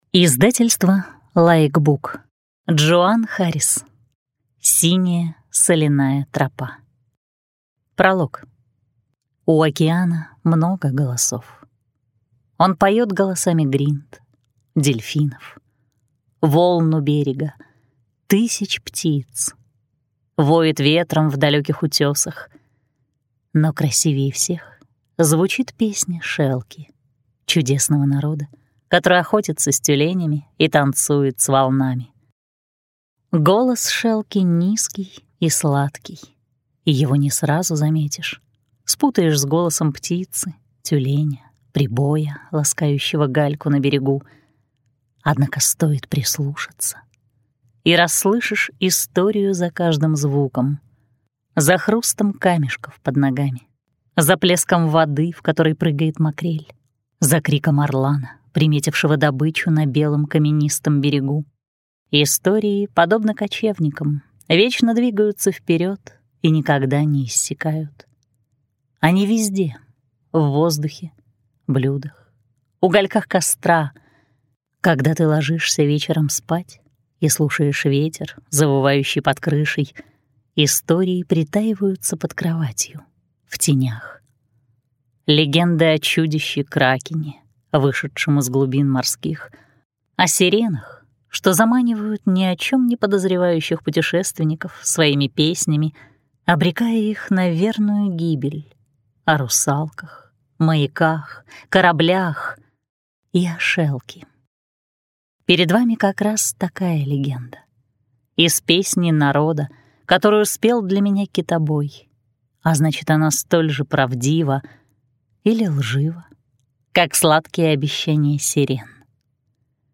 Аудиокнига Синяя соляная тропа | Библиотека аудиокниг